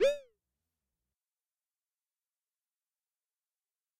jumpboost.ogg